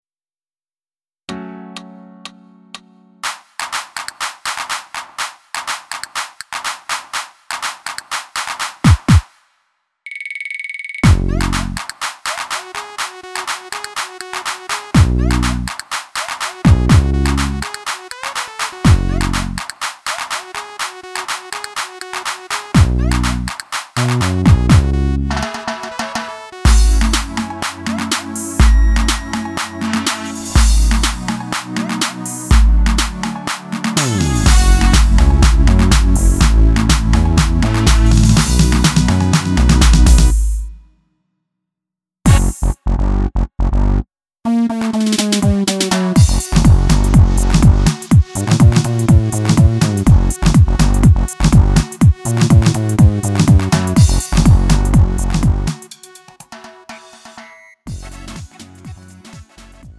음정 원키 3:22
장르 가요 구분